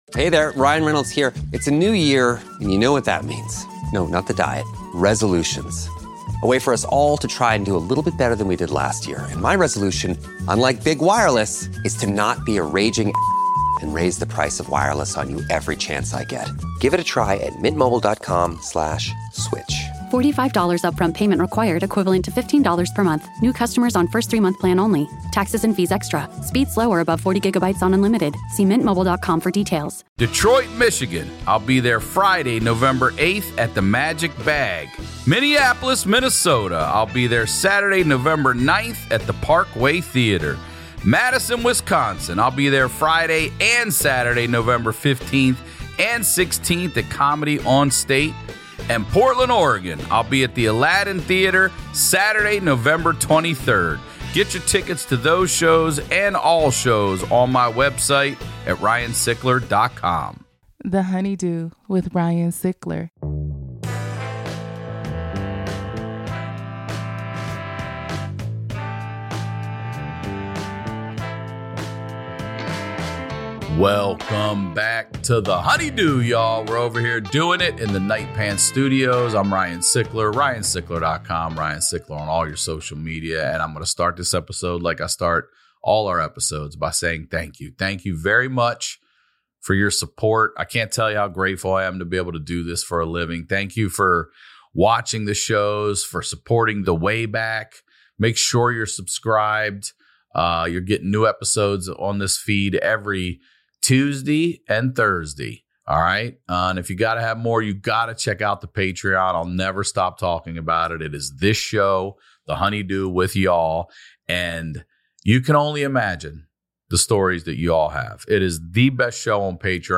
My Honeydew this week is comedian Gianmarco Soresi!